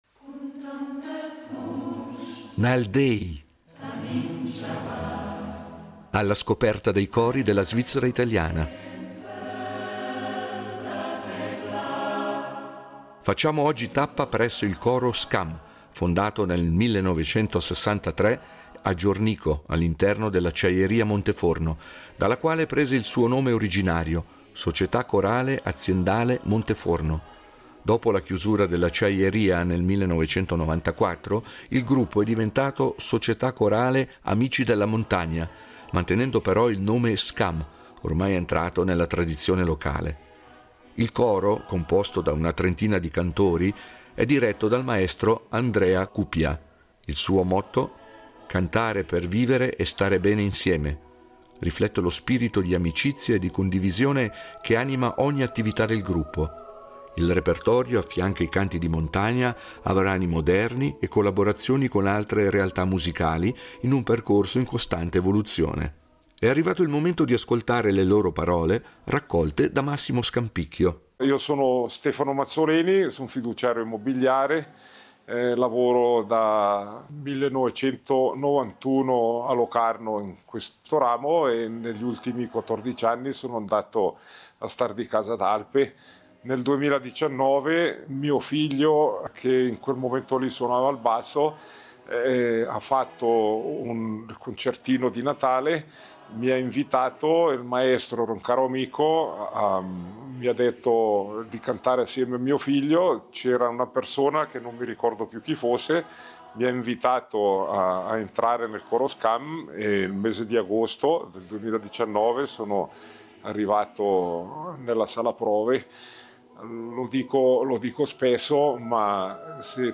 Nal déi, cori della svizzera italiana
è un coro di circa trenta cantori
un repertorio in continua evoluzione tra canti di montagna e brani moderni